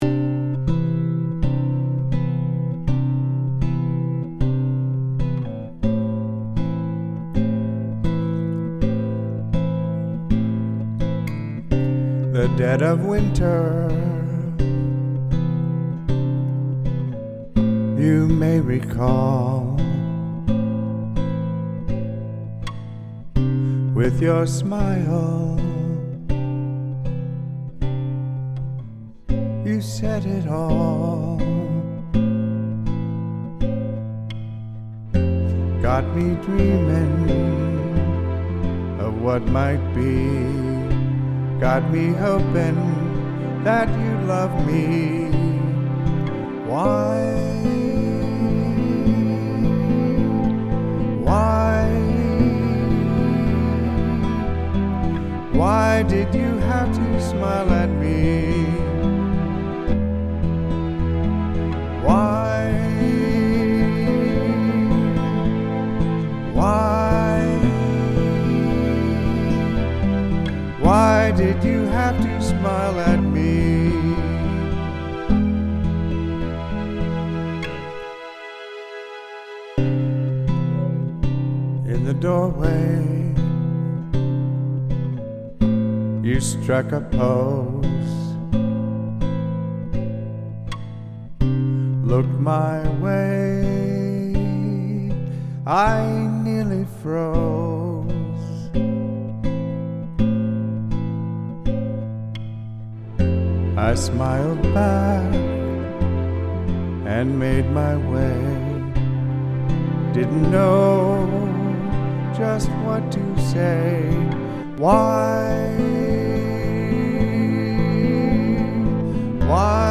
Why-Did-You-Have-To-Smile-At-Me-first-pass-with-strings2.mp3